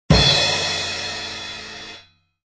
Descarga de Sonidos mp3 Gratis: bateria 2.